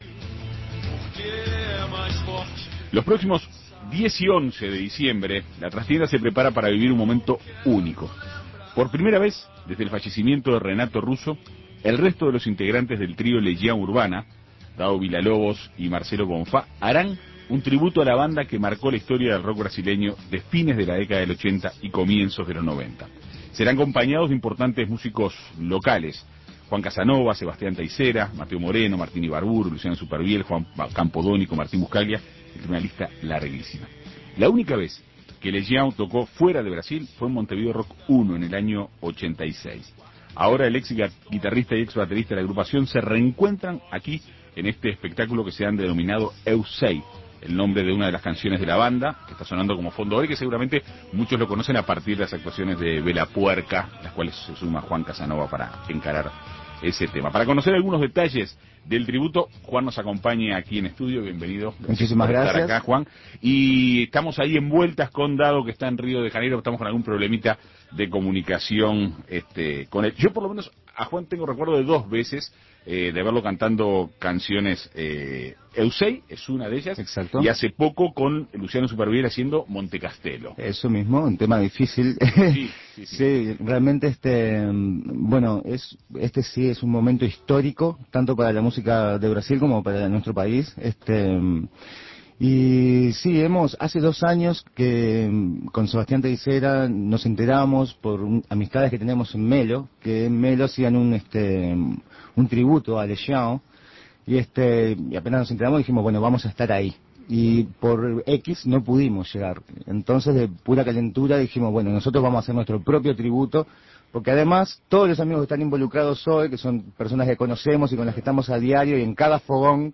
En Perspectiva Segunda Mañana dialogó este martes con Villalobos